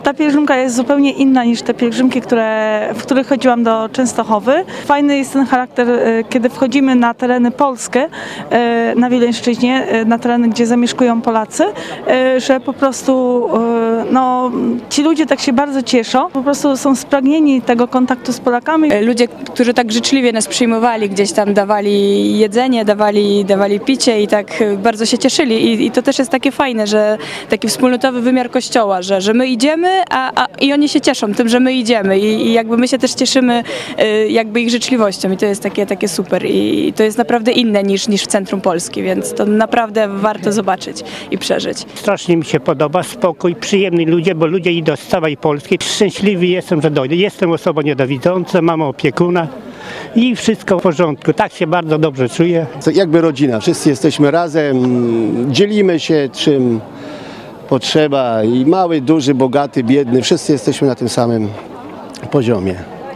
We wtorek (18.07) z mikrofonem i kamerą towarzyszyliśmy im na Litwie. Pielgrzymi chętnie opowiadali o wrażeniach z pierwszych dni drogi i spotkaniach z Polakami z Litwy.